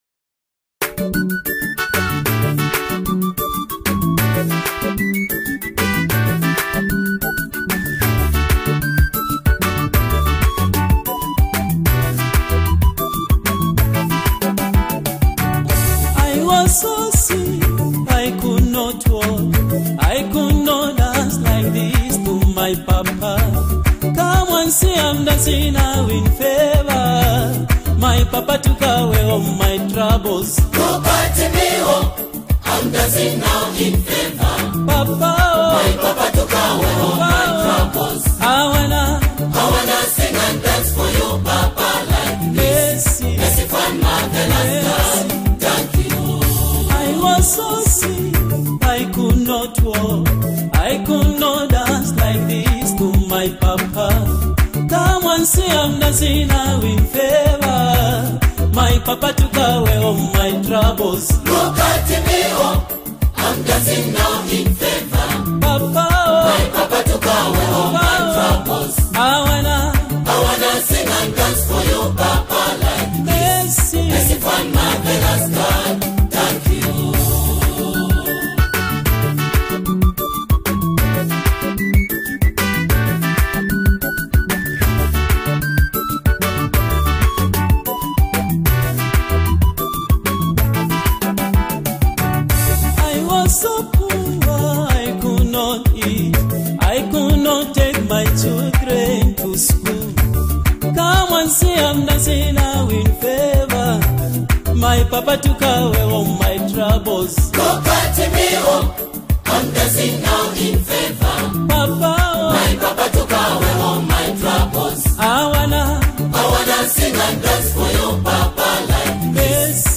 Kalindula
Latest Zambian Worship Song
The choir’s harmonic arrangement and powerful delivery